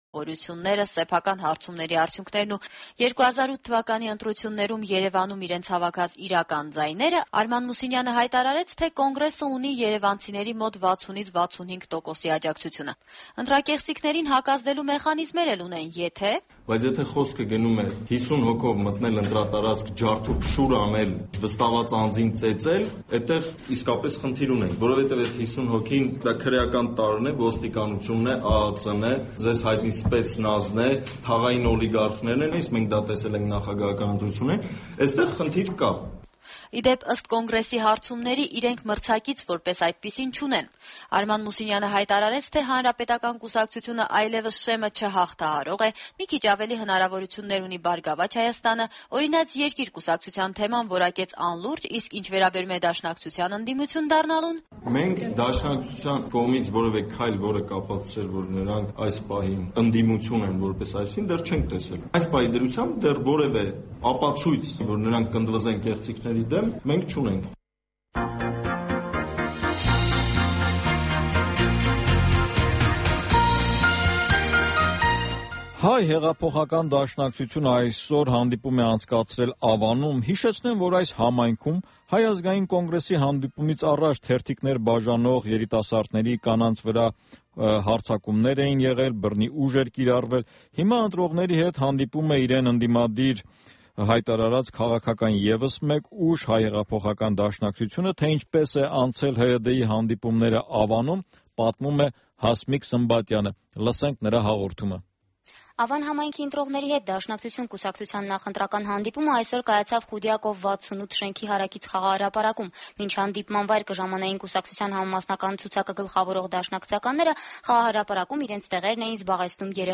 Ժիրայր Լիպարիտյանի հարցազրույցը